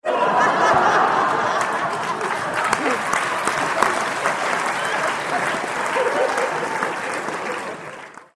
دانلود آهنگ خنده دسته جمعی 3 از افکت صوتی انسان و موجودات زنده
دانلود صدای خنده دسته جمعی 3 از ساعد نیوز با لینک مستقیم و کیفیت بالا
جلوه های صوتی